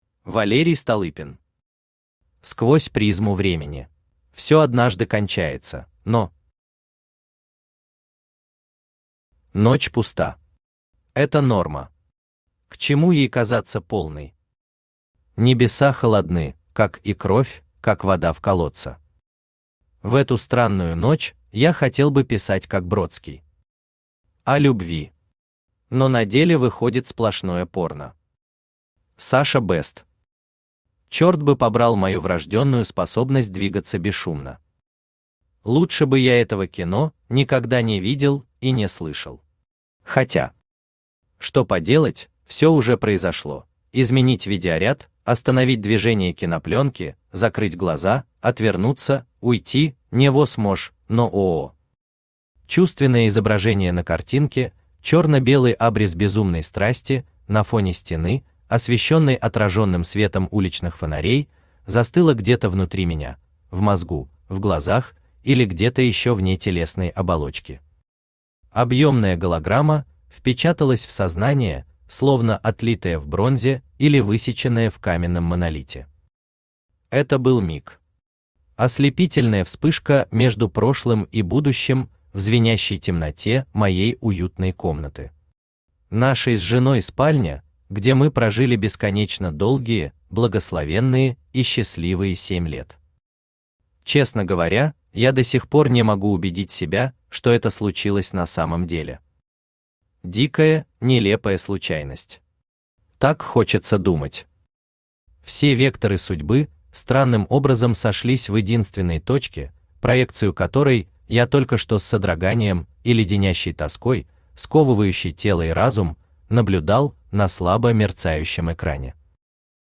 Aудиокнига Сквозь призму времени Автор Валерий Столыпин Читает аудиокнигу Авточтец ЛитРес.